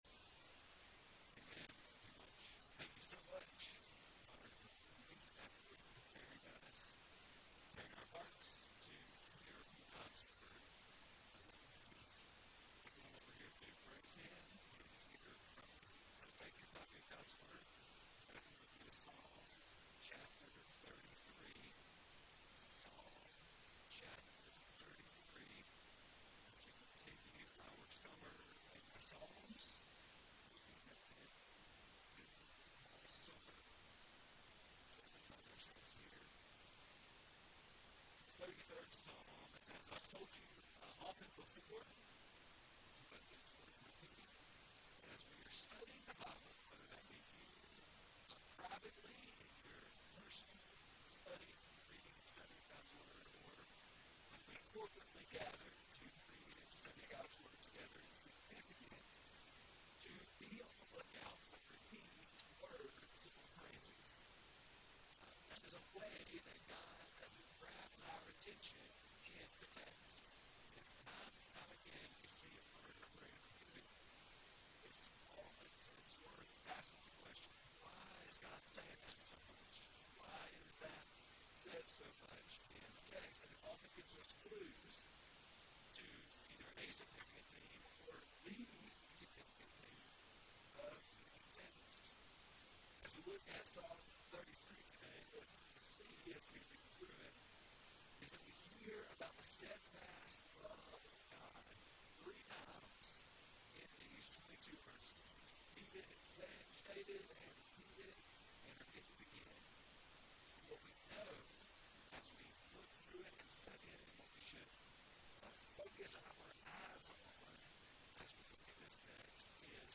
July 6, 2025 Sermon Audio.mp3